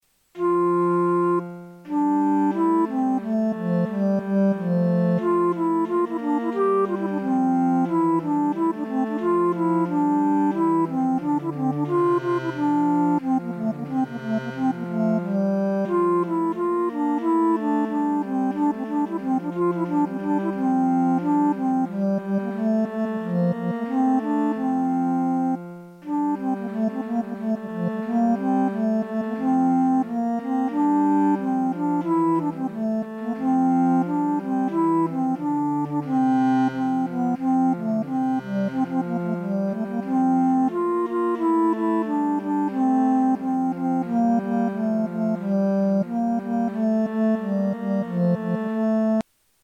Et gaudebit — (tenor vocale + duplum strumentale) | Ensemble Anonymus | Fleurs de Lys, 1995